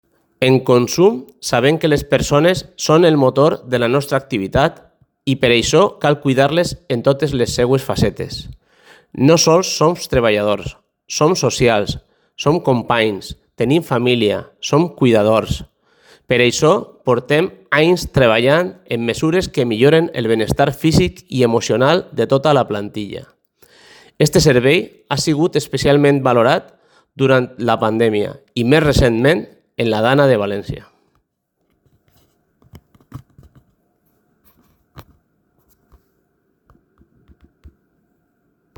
Tall de veu